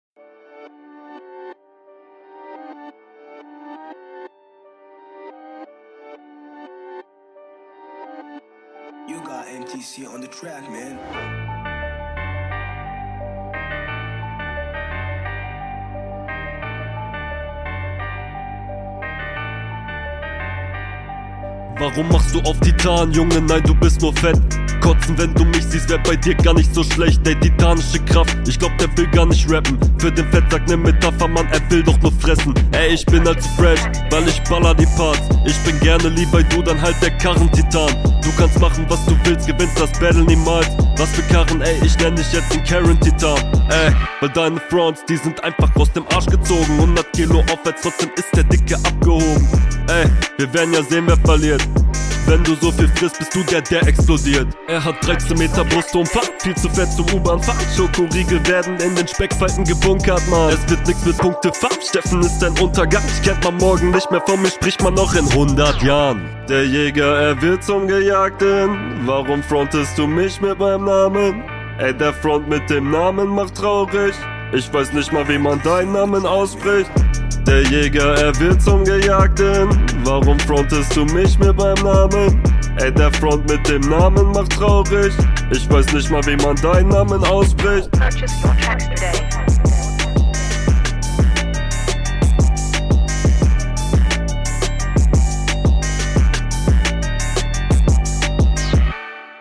Flowlich deinem Gegner unterlegen, aber sehr coole Punches und style taugt auch.